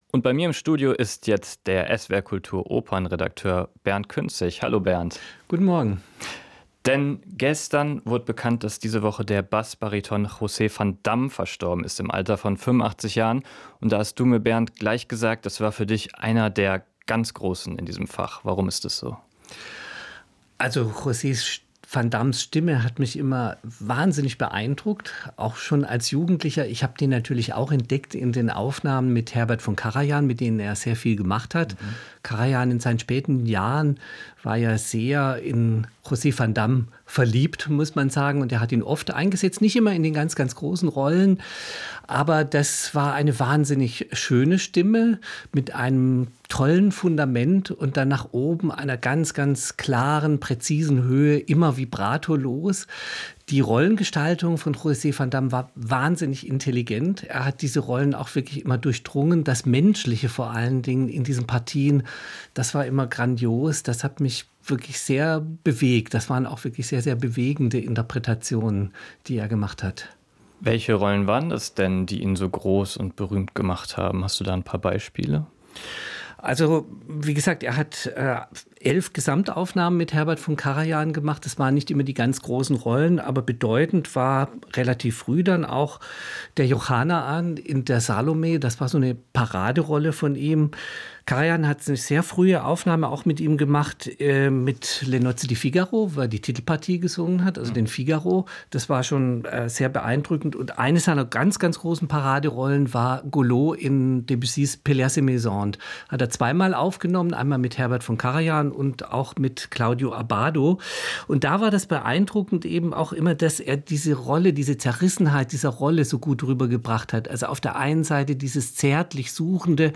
Musikgespräch